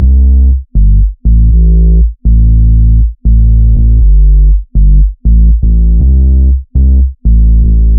Trabajo audio » Suspiro opimista calmado